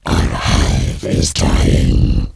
alien_hivedying2.wav